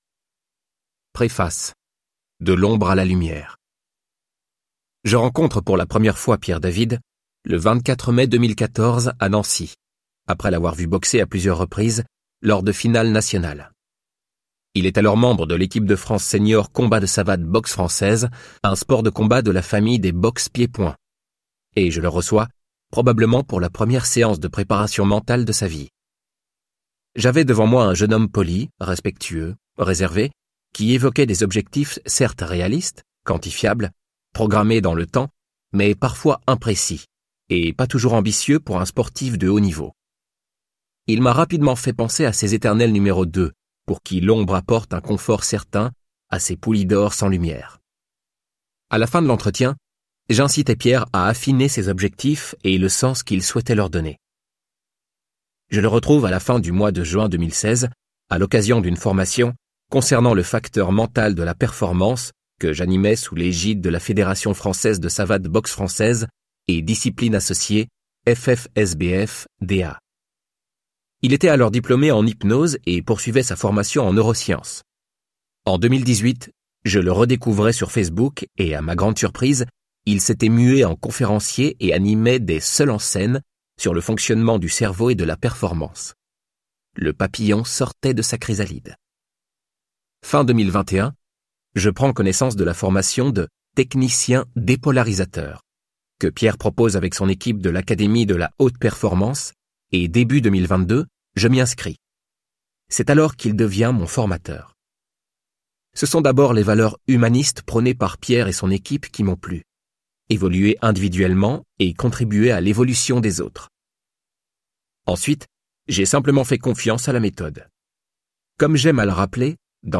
Vous avez l'impression de stagner ?Vous êtes au top techniquement, tactiquement et physiquement mais vous perdez vos moyens en milieu compétitif ?Ce livre audio est pour vous !